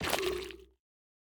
Minecraft Version Minecraft Version snapshot Latest Release | Latest Snapshot snapshot / assets / minecraft / sounds / block / sculk / place2.ogg Compare With Compare With Latest Release | Latest Snapshot